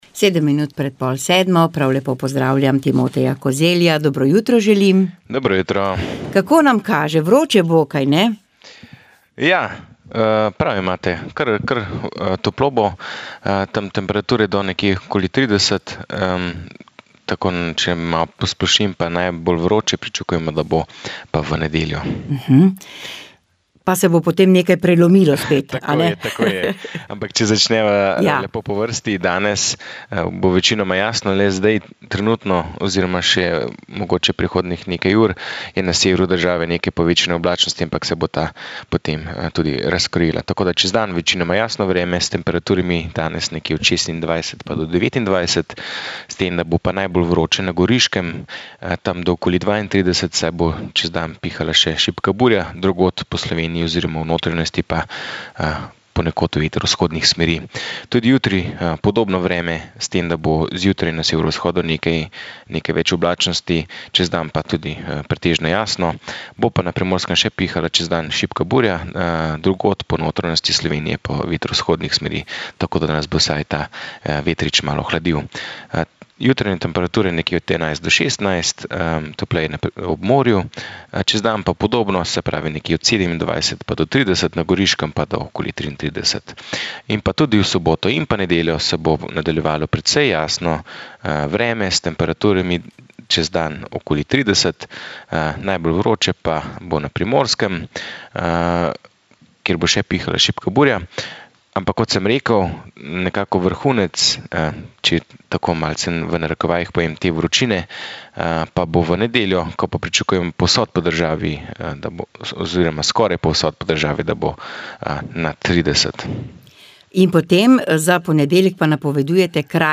Vremenska napoved